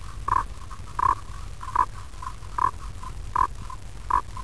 Identify the following calls of frogs.
Frog 3